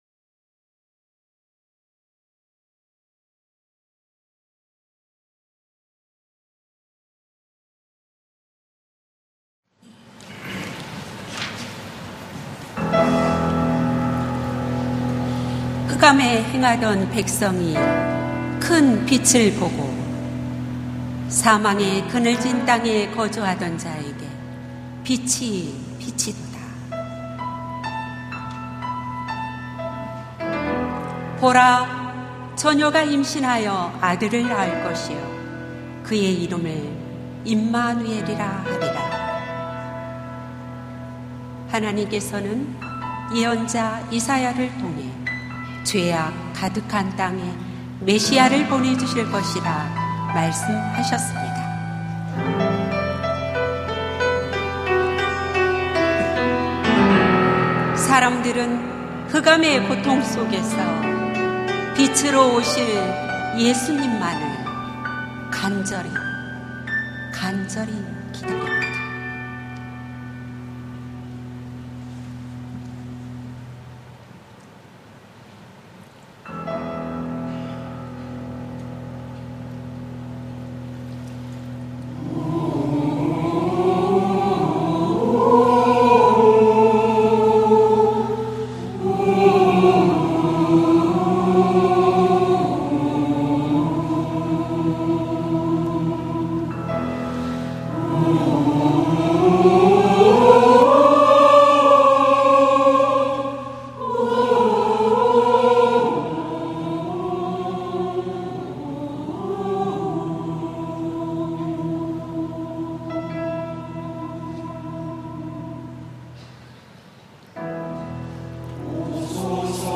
성탄절 칸타타